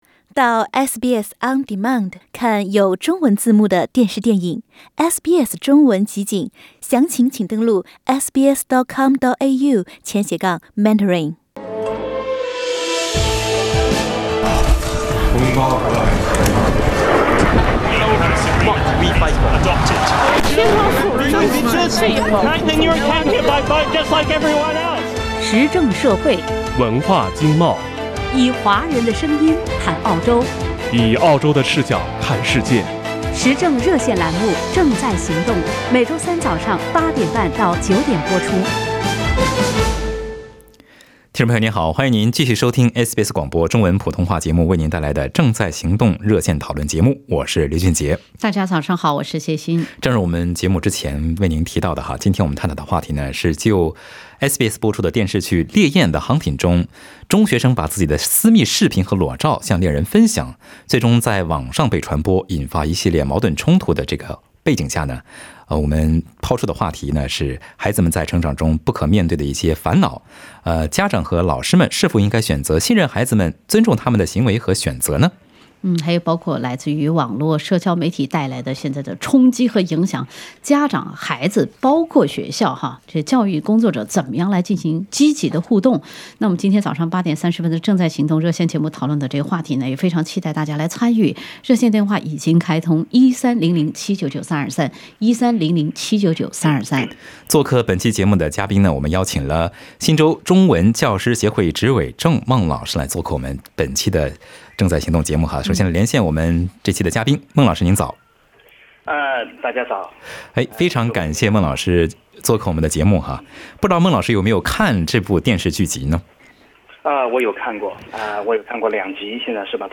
在节目中，有听众朋友打入电话分享了他们的看法。